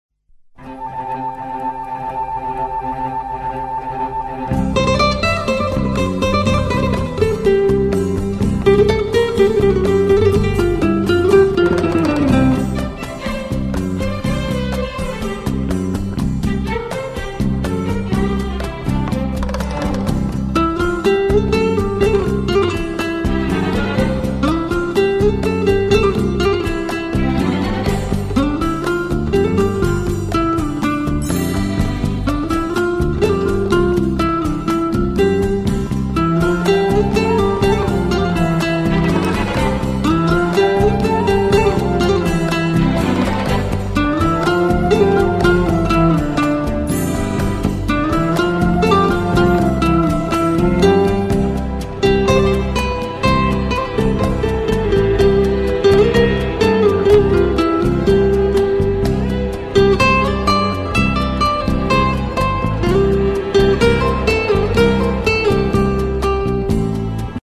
Guitar Music